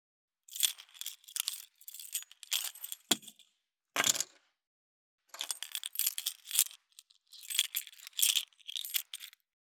167.鍵【無料効果音】